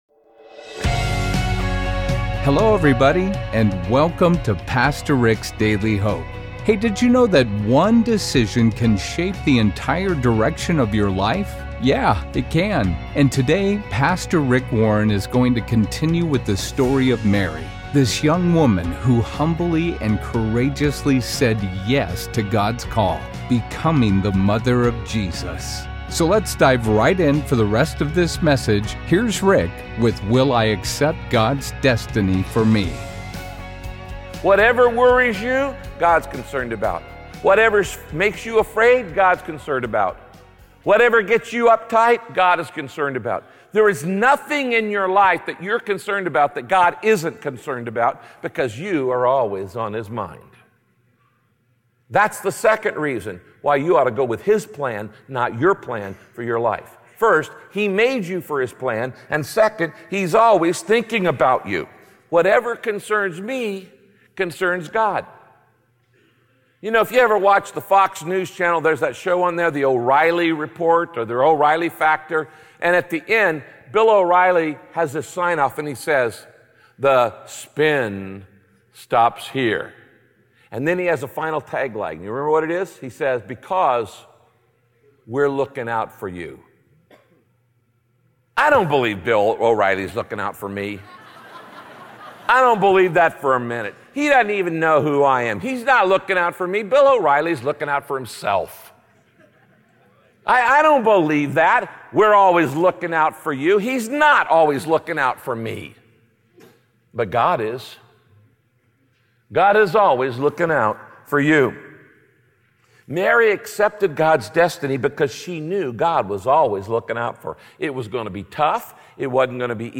In this message, Pastor Rick explains how God blesses and keeps his promises to people who follow him with humility.